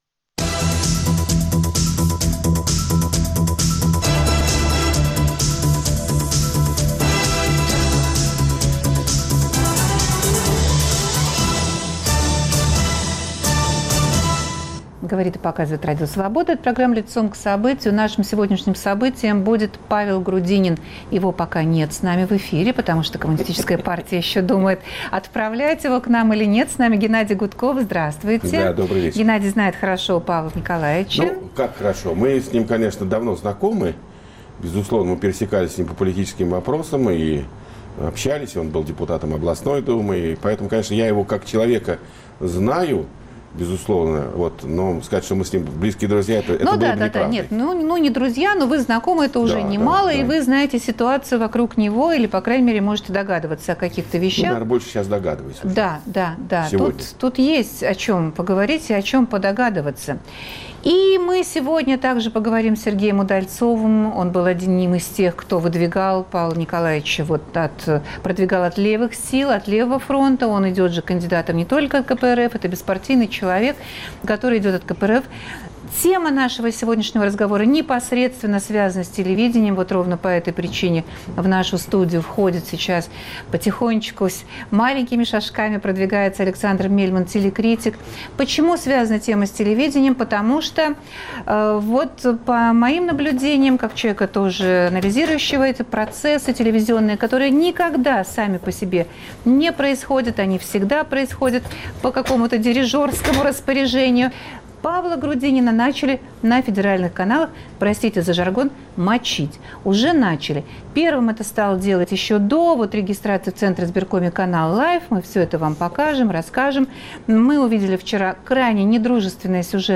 Что именно инкриминируют Павлу Грудинину, и чем он не угодил Кремлю, который определяет политику СМИ? Обсуждают политики Геннадий Гудков, Сергей Удальцов ("Левый фронт") и Дмитрий Новиков (КПРФ).